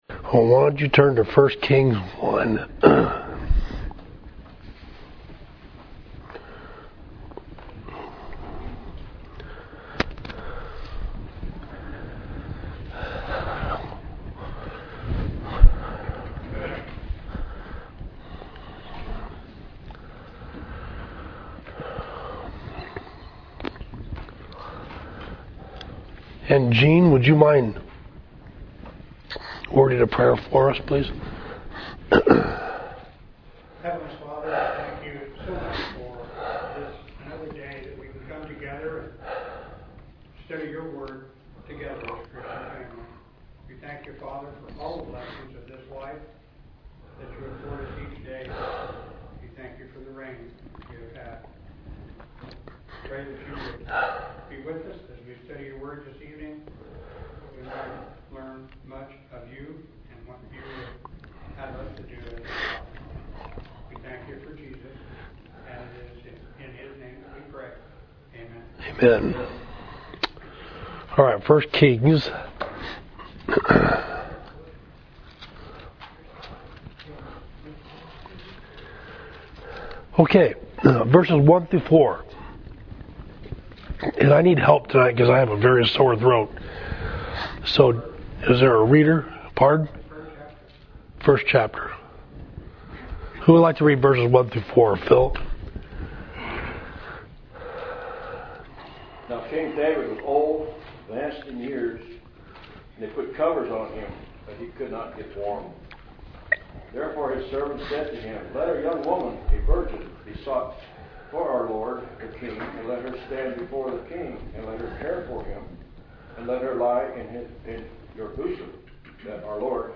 Class: Adonijah or Solomon, First Kings 1